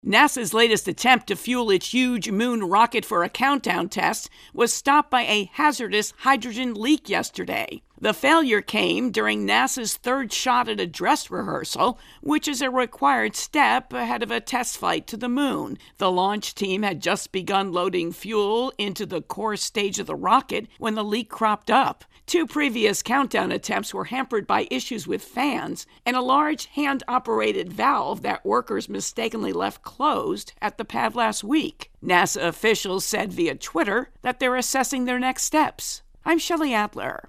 NASA intro and voicer